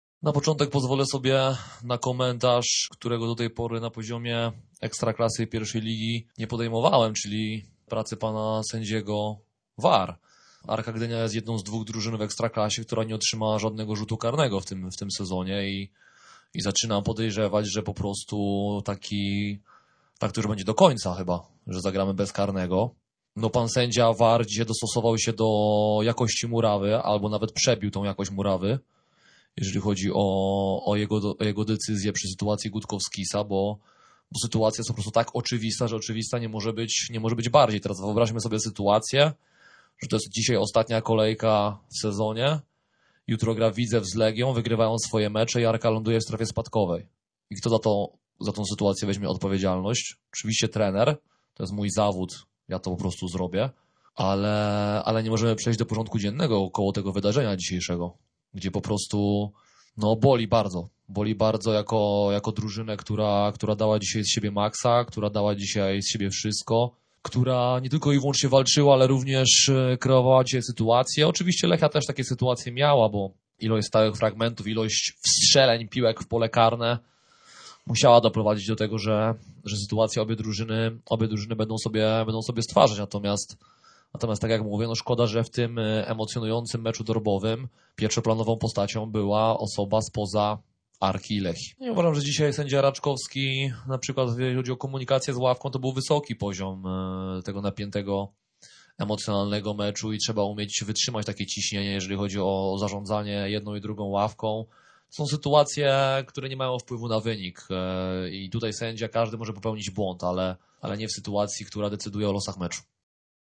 Na pomeczowej konferencji krytycznie do decyzji arbitrów